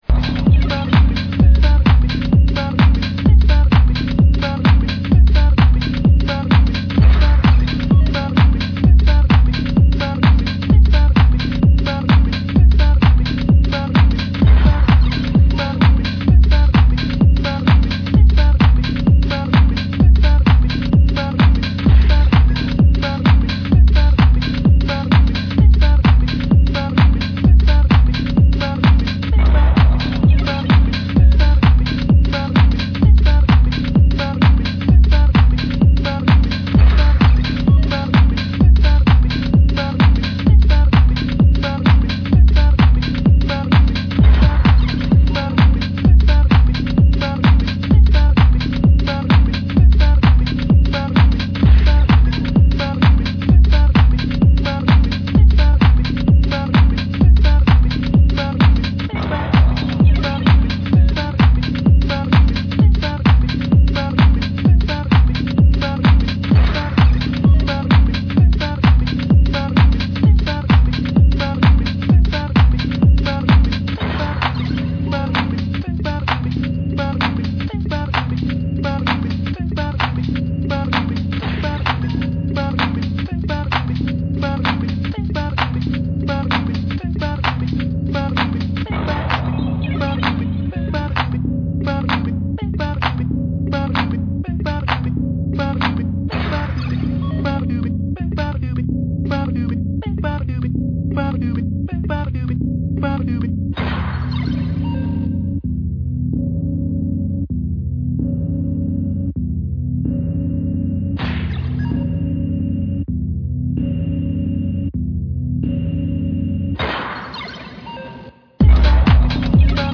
bouncy garage beats